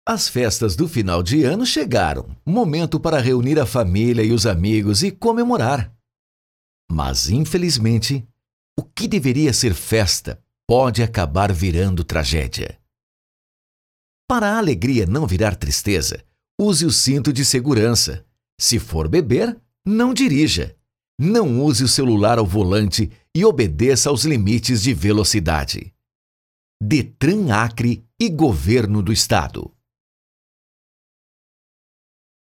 (inicio num tom conversado e carismático
(essa parte pode ficar mais dramático)